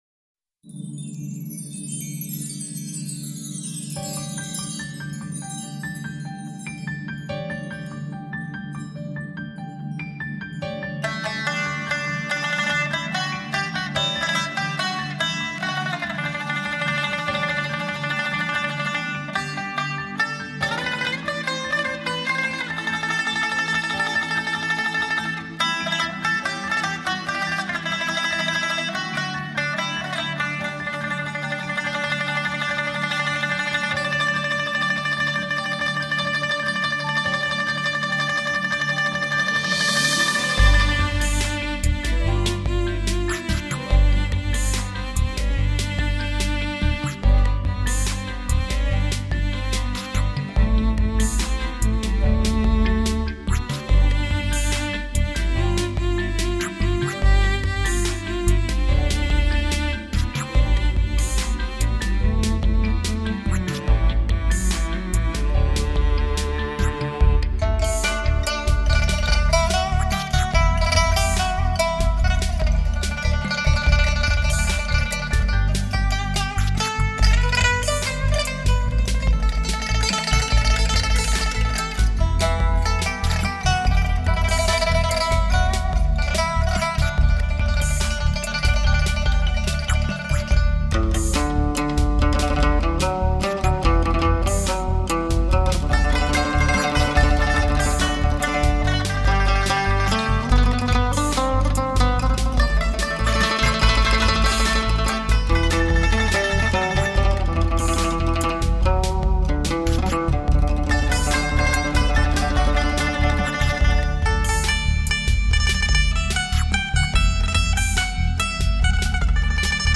◆HD直刻无损高音质音源技术